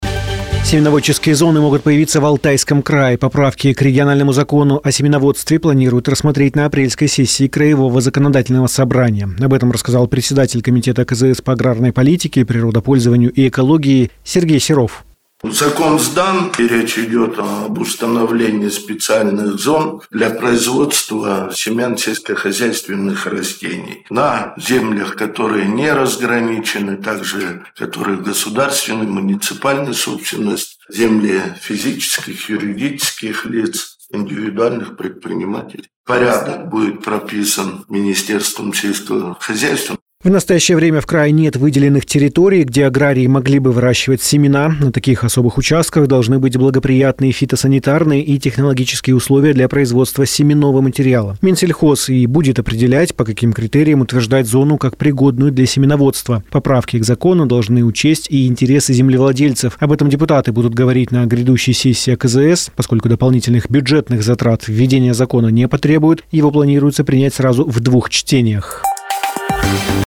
Сюжет на Business FM (Бизнес ФМ) Барнаул